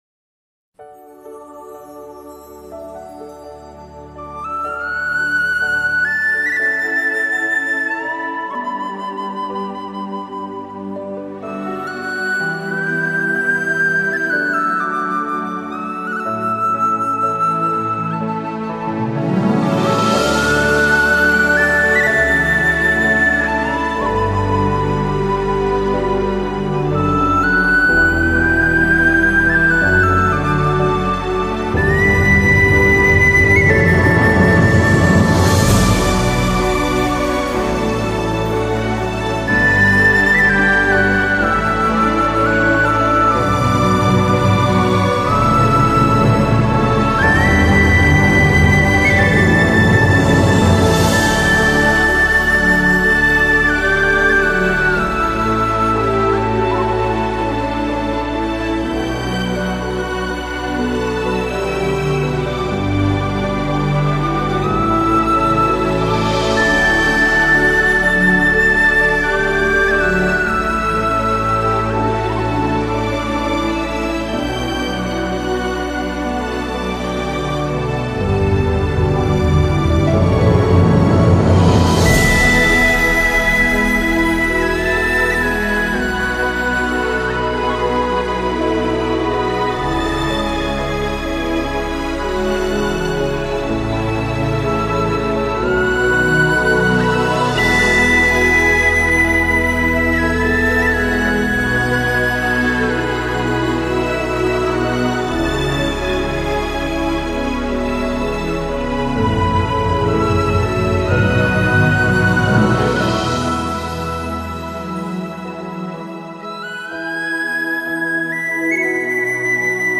钢琴及电子